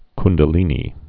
(kndə-lēnē)